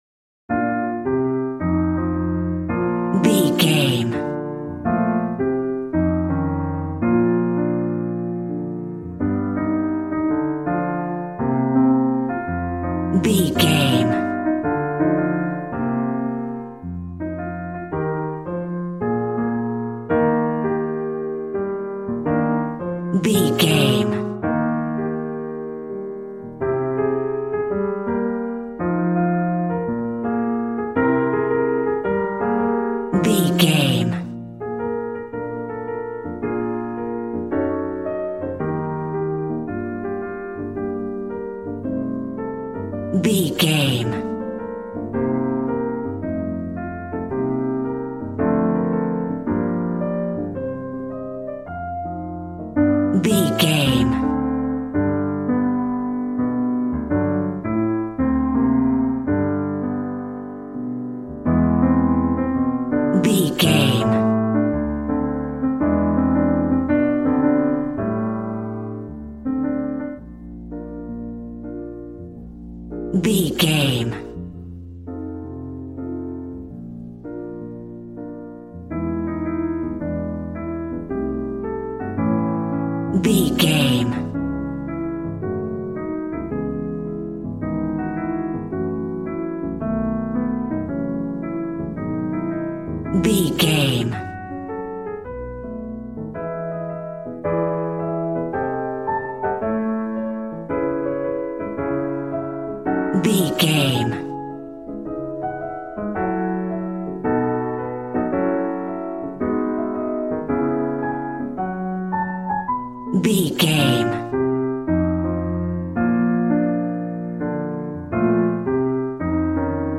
Smooth jazz piano mixed with jazz bass and cool jazz drums.,
Ionian/Major
B♭
smooth
drums